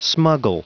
Prononciation du mot smuggle en anglais (fichier audio)
Prononciation du mot : smuggle